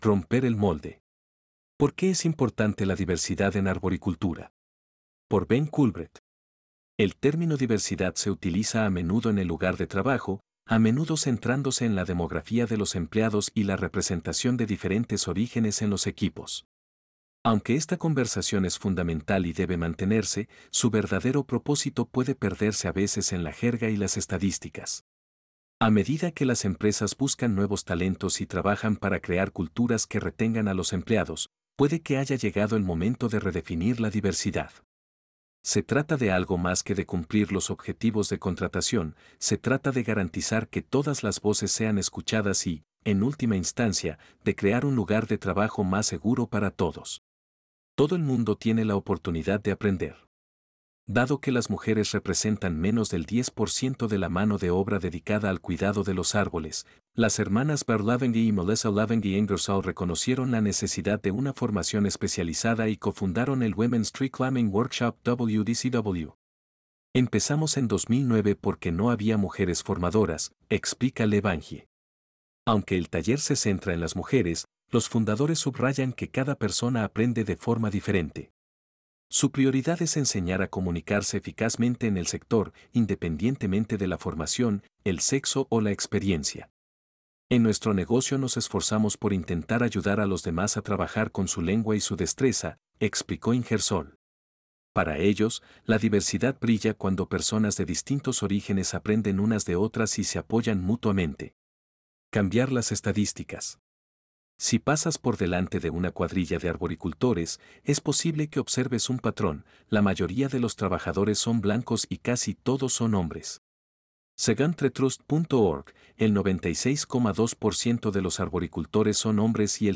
Este es nuestro intento de convertir las historias en audio español usando Inteligencia Artificial.